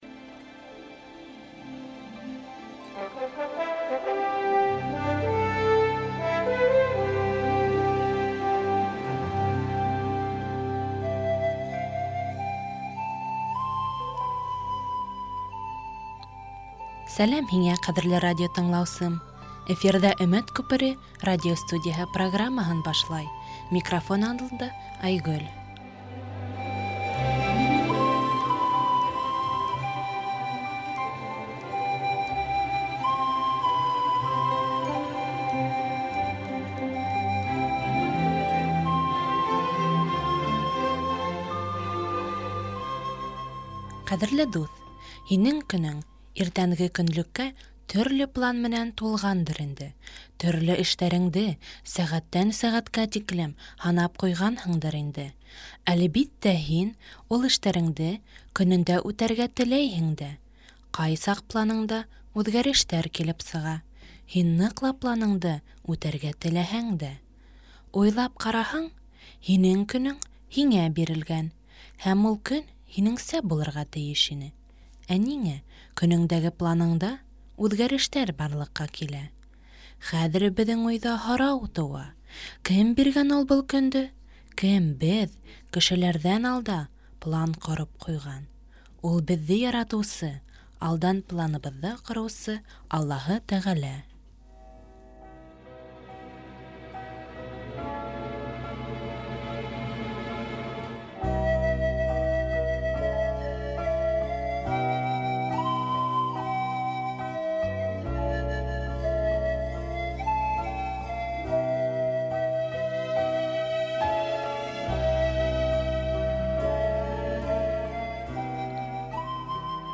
Вәғәздәр | ИНЖИЛ БАШҠОРТ ХАЛҠЫНА
Бында һеҙ рухи уйланыуҙар (ҡыҫҡа вәғәздәр) тыңлай алаһығыҙ.